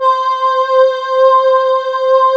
Index of /90_sSampleCDs/USB Soundscan vol.28 - Choir Acoustic & Synth [AKAI] 1CD/Partition D/14-AH VOXST